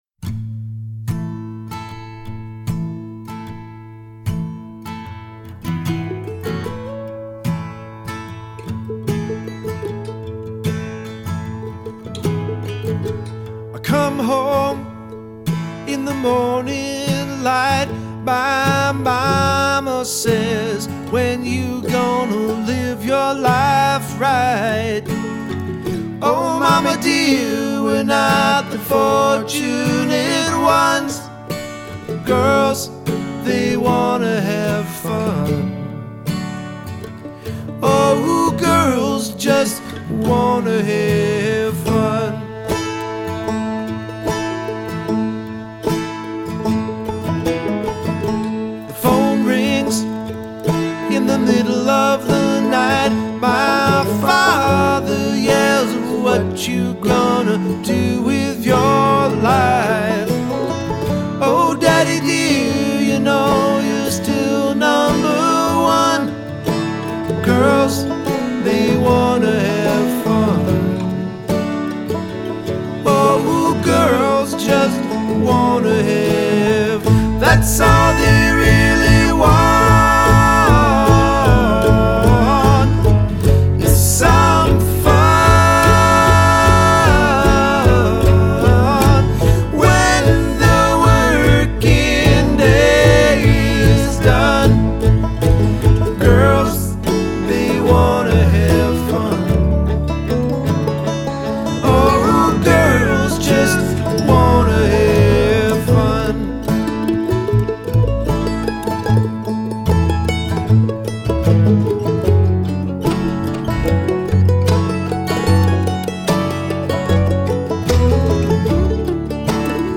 all male blue grass version